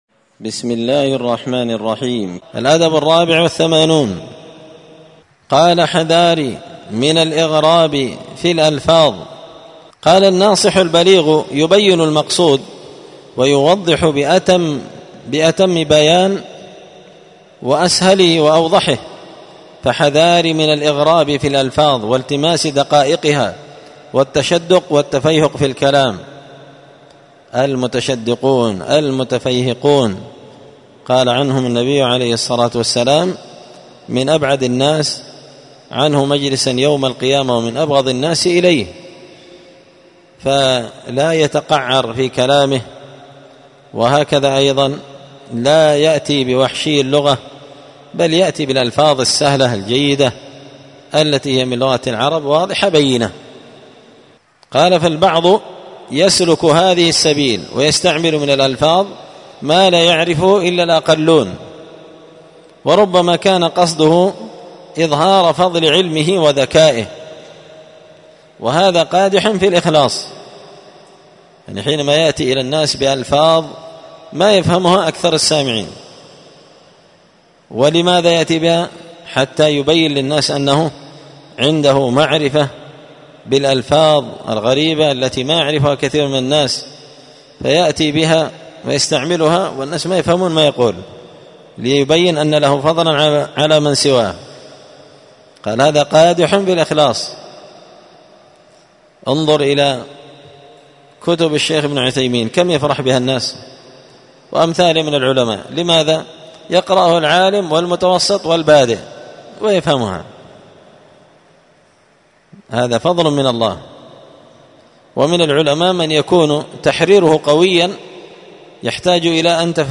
94الدرس-الرابع-والتسعون-من-النبذ-في-آداب-طالب-العلم-الأدب-الرابع-والثمانون-حذار-من-الإغراب-في-الألفاظ.mp3